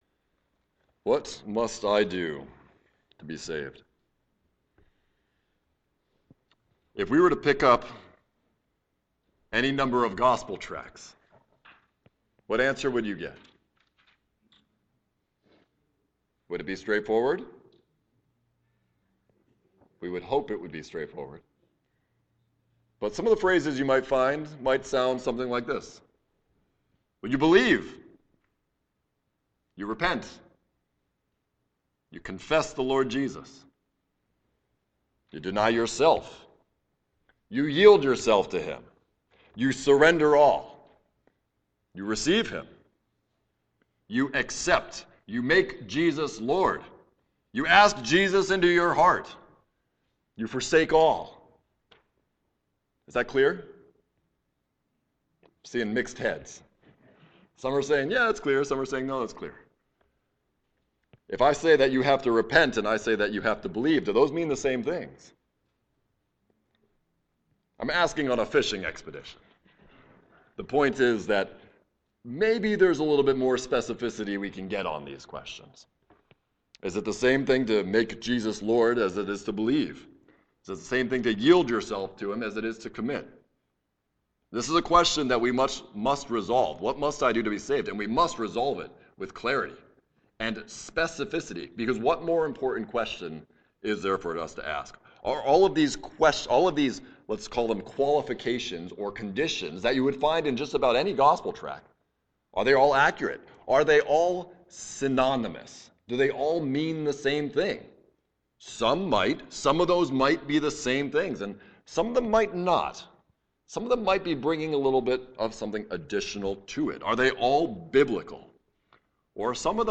Evening Service
Sermon